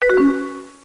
Added AIM sfx
imrecv.ogg